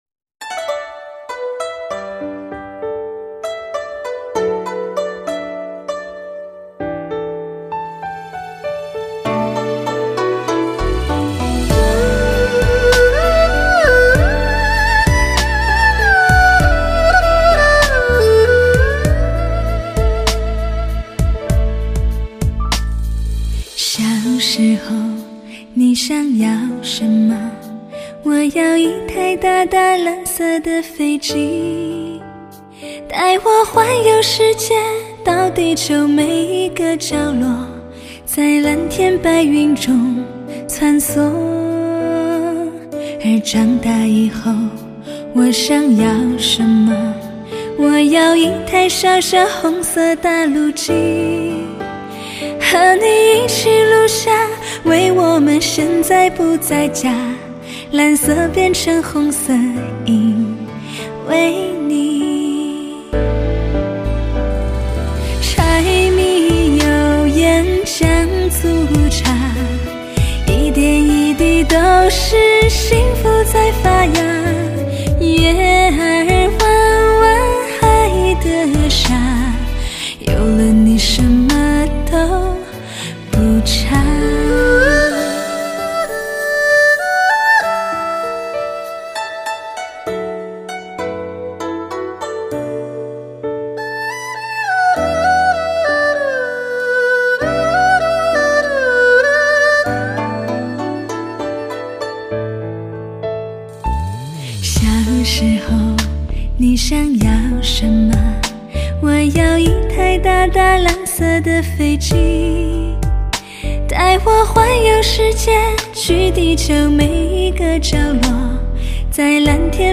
难得一听的唯美女声，声声入醉，
火爆金曲，HIFI品质，阵容华丽，声声入耳，极度享受，绝不错过！